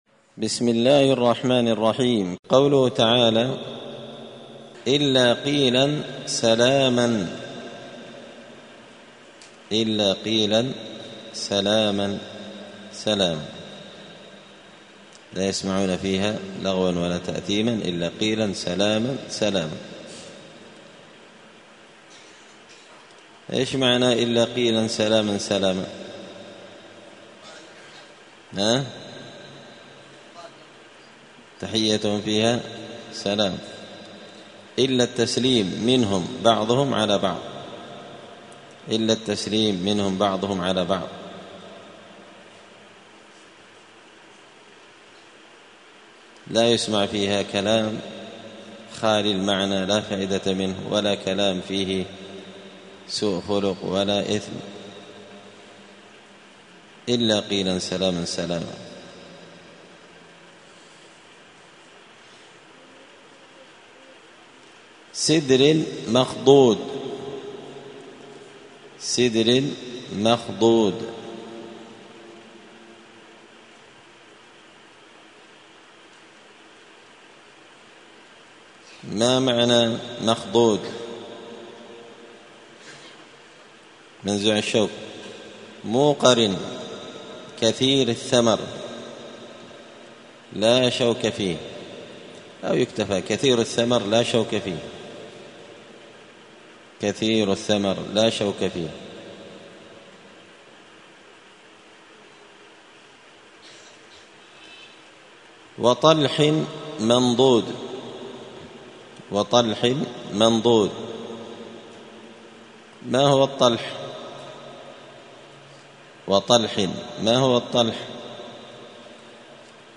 *(جزء الذاريات سورة الواقعة الدرس 188)*